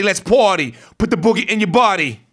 RAPHRASE02.wav